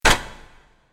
snd_mart_flap.wav